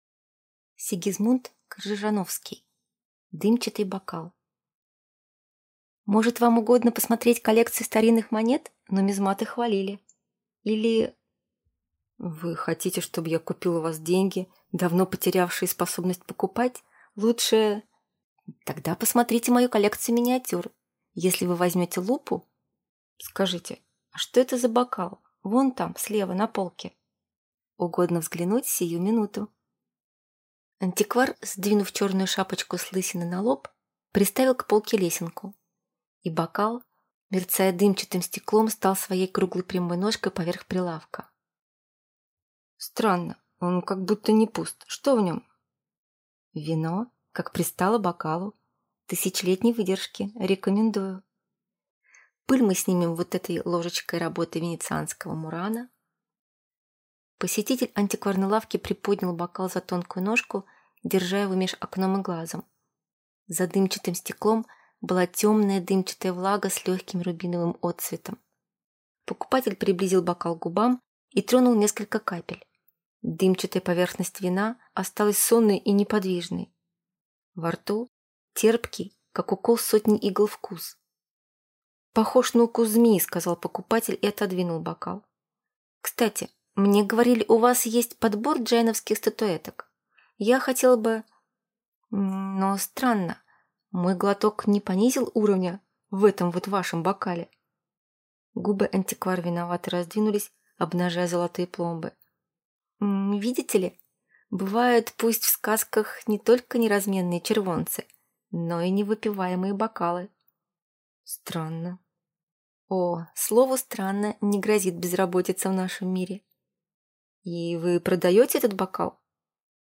Аудиокнига Дымчатый бокал | Библиотека аудиокниг